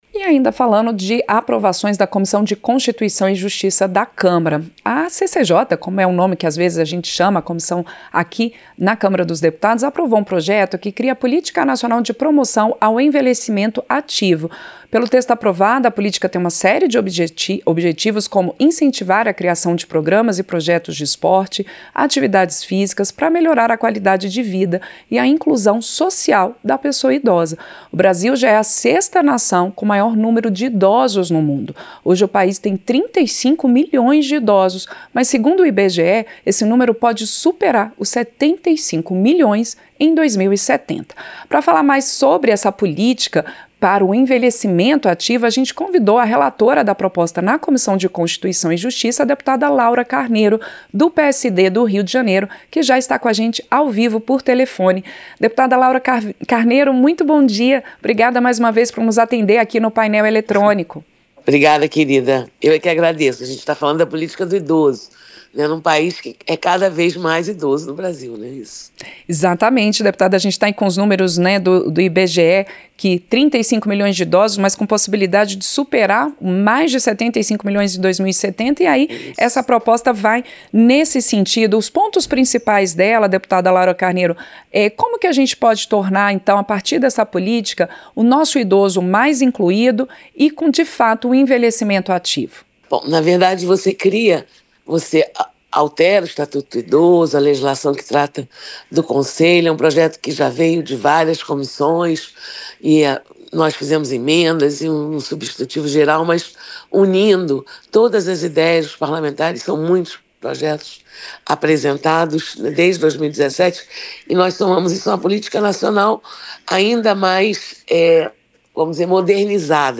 Programa ao vivo com reportagens, entrevistas sobre temas relacionados à Câmara dos Deputados, e o que vai ser destaque durante a semana.
Entrevista - Dep. Laura Carneiro (PSD-RJ)